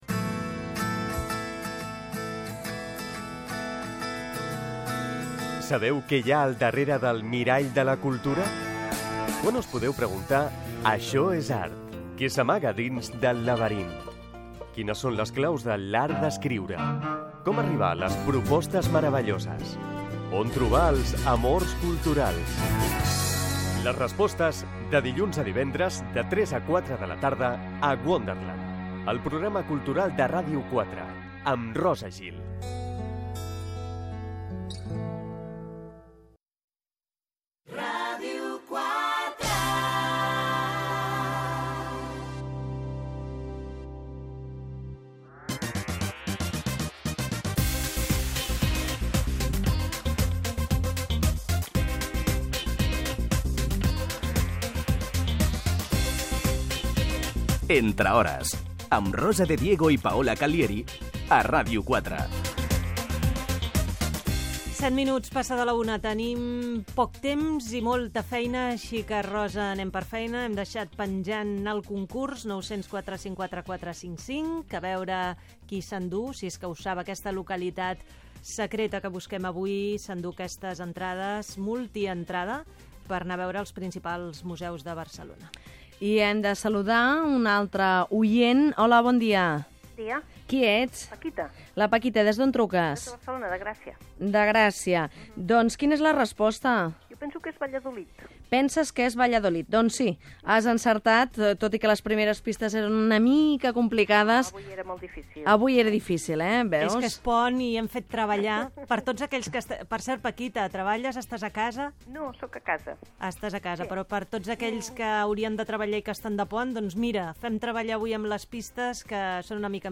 indicatiu de la ràdio, hora, concurs del programa
Info-entreteniment